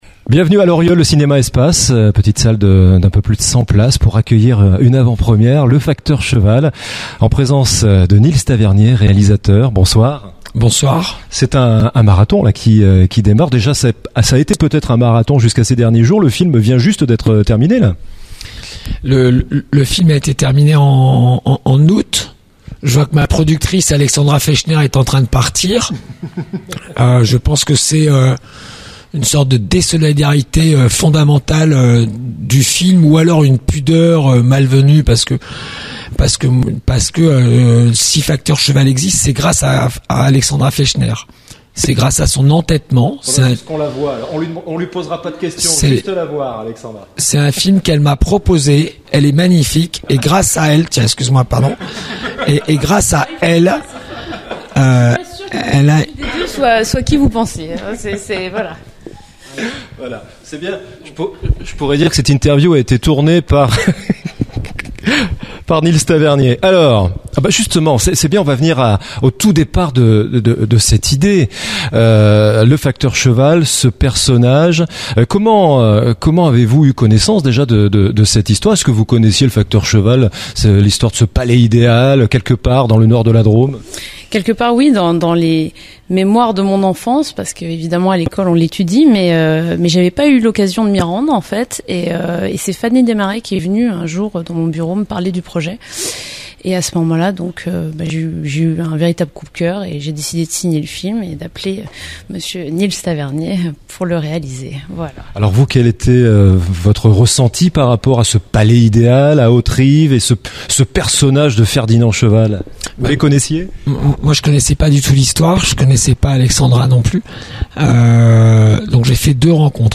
FACTEUR CHEVAL Le Film - Première Inteview ! avec Nils TAVERNIER